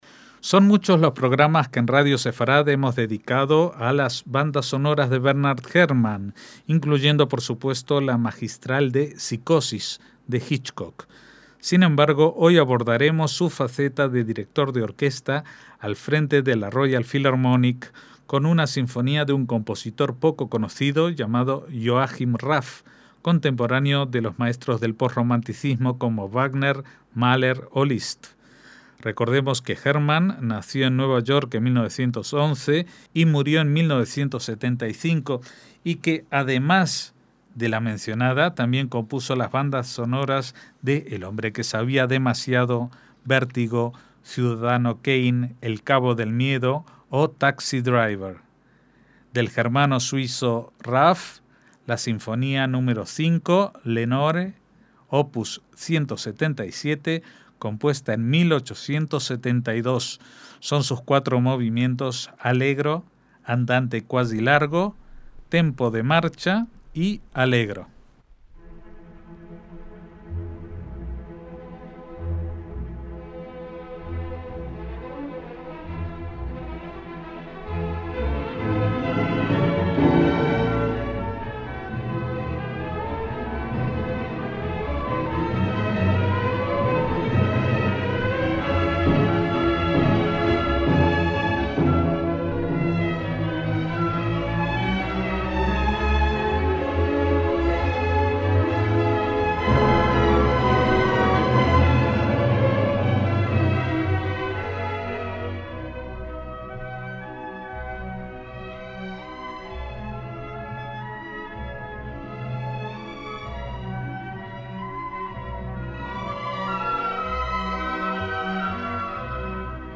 MÚSICA CLÁSICA
al frente de la Filarmónica de Londres
en mi mayor
Son sus partes y movimientos: Parte I: La felicidad del amor (Allegro; Andante cuasi larghetto); Parte II: La